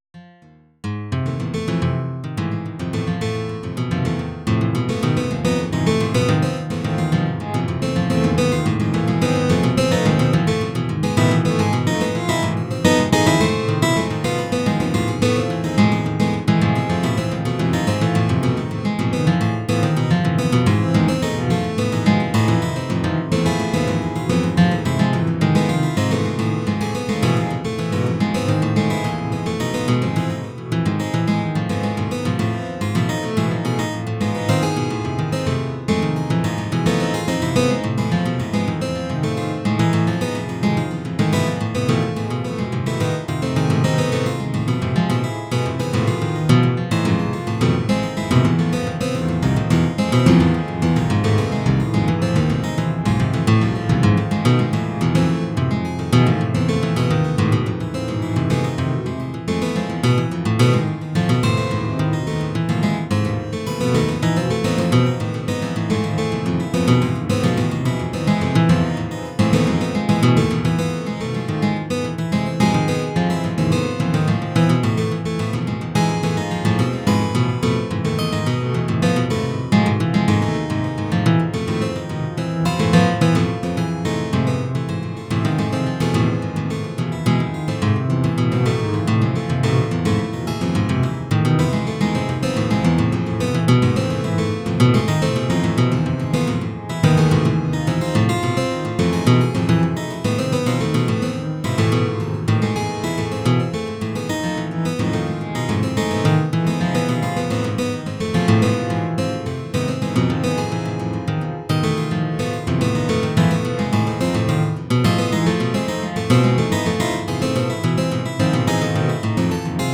エレクトリック・アコースティック・ギターを使用しての
即興的タッピング演奏の仮想ライブ。
with both hands tapping technique
this is the multi-layered simulated guitars album.